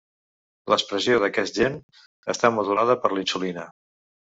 Pronounced as (IPA) [əsˈta]